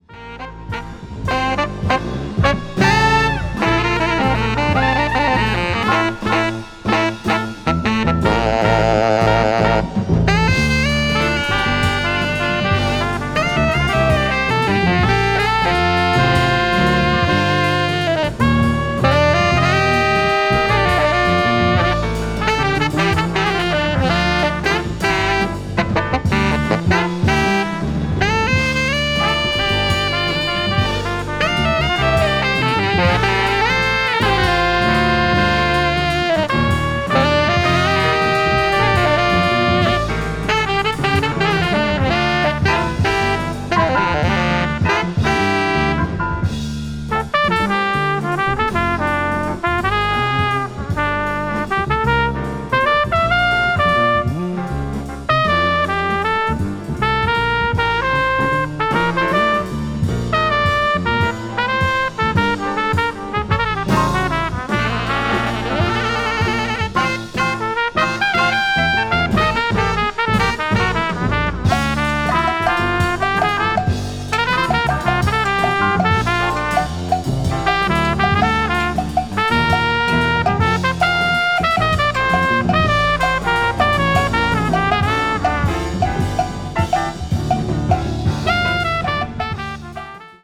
modal jazz   post bop   spritual jazz